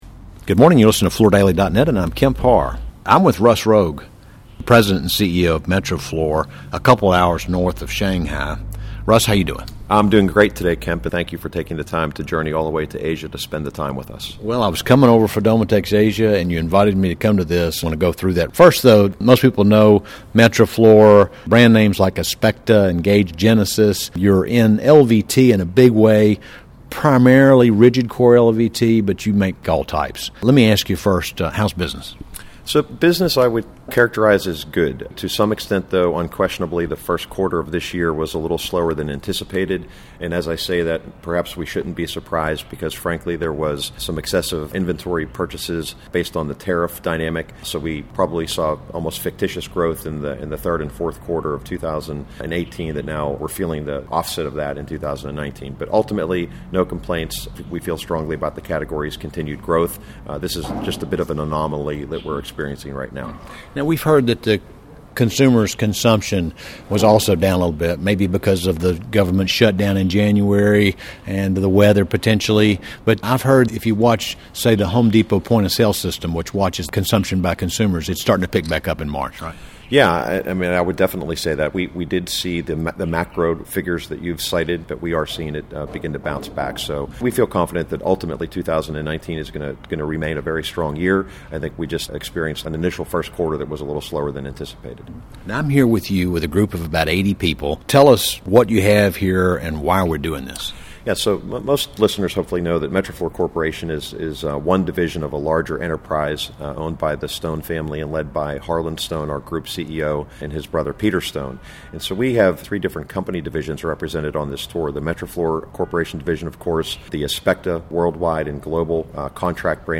Listen to the interview to get a quick summary of Metroflor's two primary factories just north of Shanghai in China.